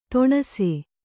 ♪ toṇase